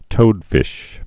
(tōdfĭsh)